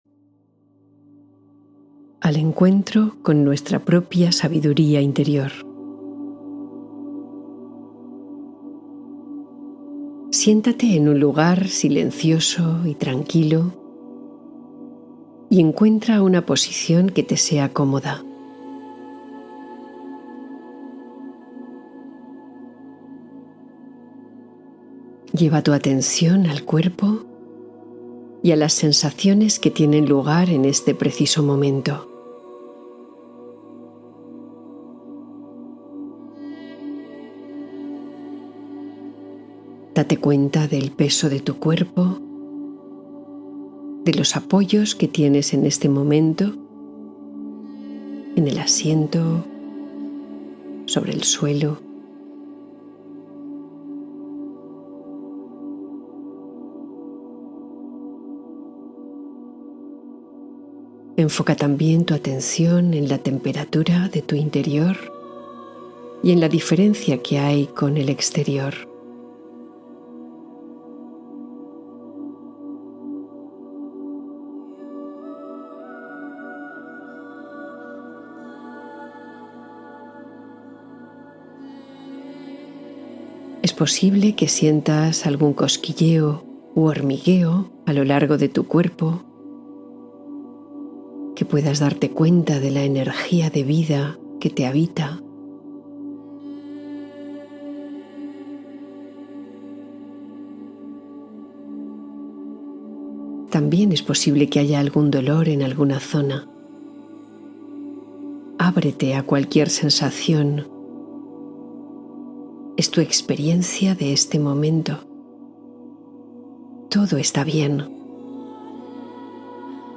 Sabiduría interior: práctica meditativa de conexión consciente